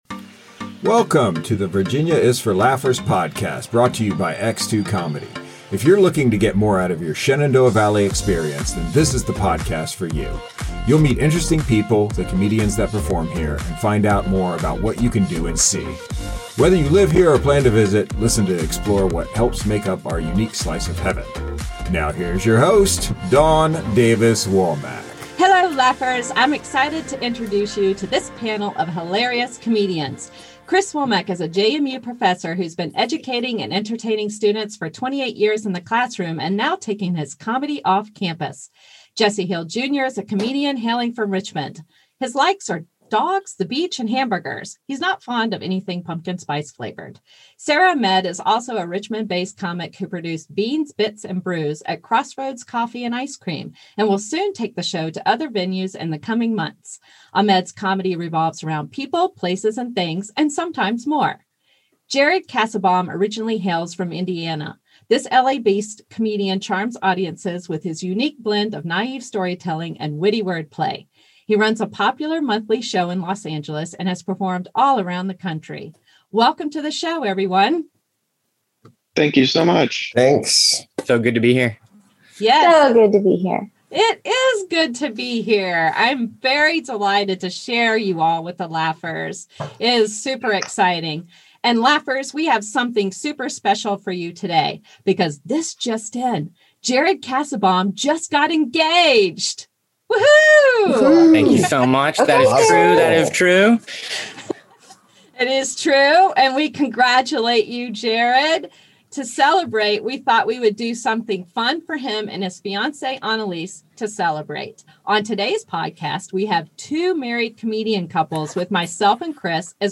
Join us this fun panel of two married comedian couples and a newly engaged comedian who don’t take them selves too seriously.